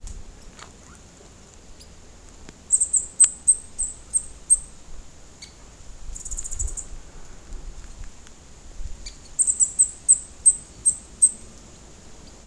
speckled-piculate-call